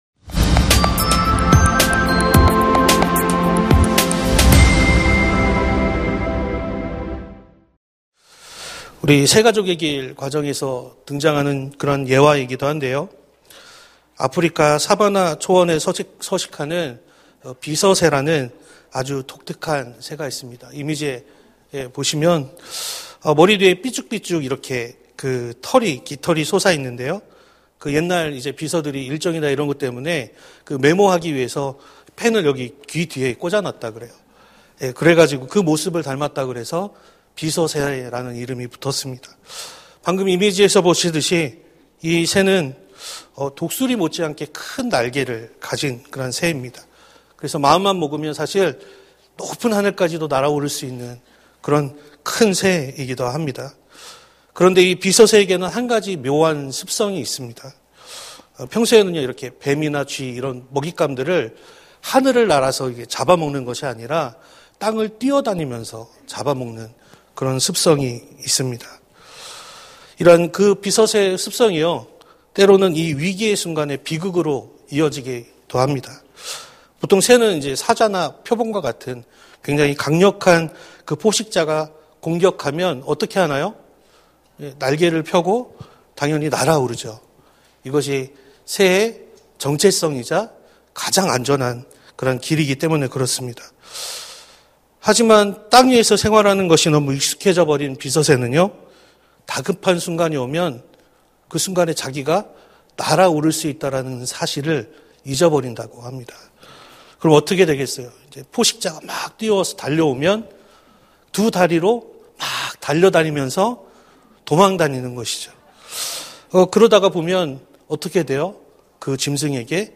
설교 : 수요향수예배 (수지채플) 소금으로 빛으로! 설교본문 : 마태복음 5:13-16